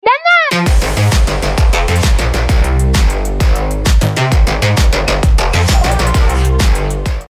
Здесь вы найдете забавные, мотивирующие и необычные аудиоэффекты, которые можно использовать при получении донатов.
Малыш ликует от доната